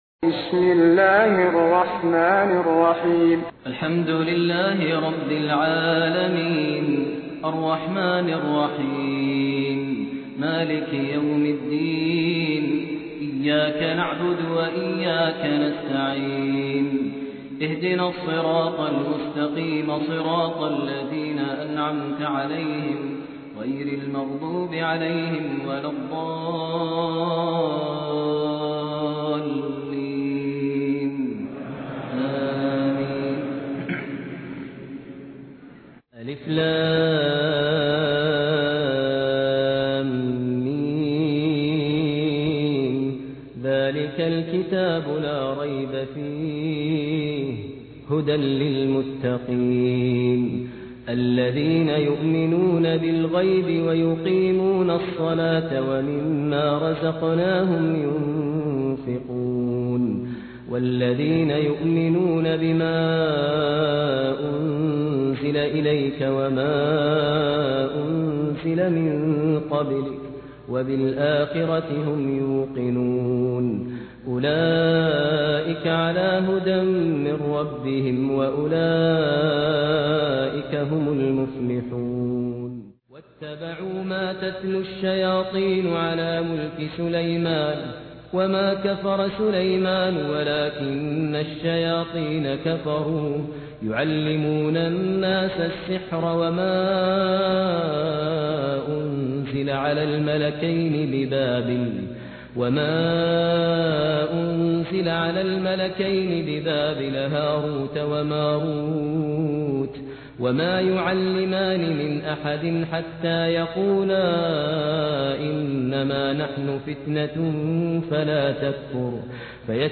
بصوت الشيخ ماهر المعيقلي